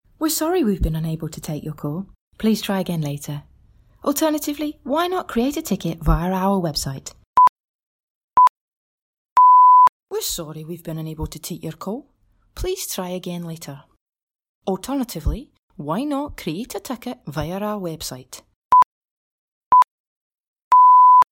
Corporate Phone Message – 2 accent options